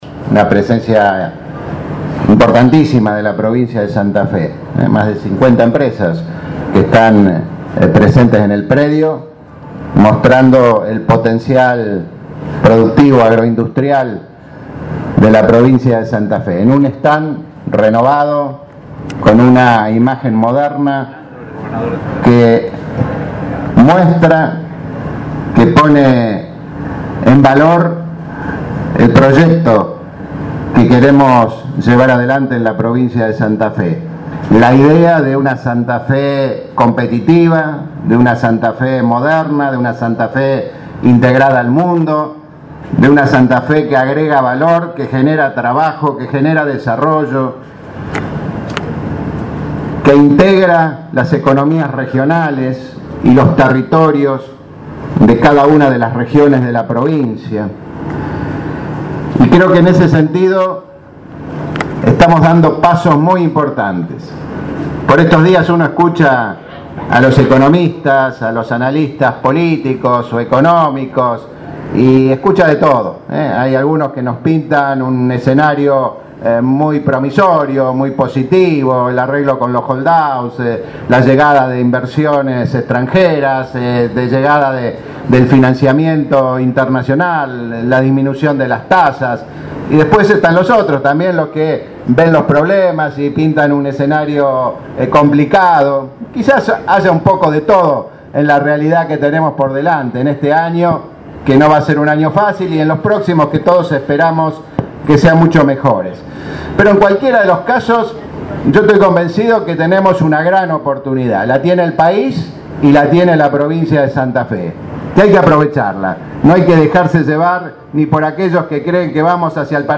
Desarrollo Productivo Lifschitz en Expoagro 2016.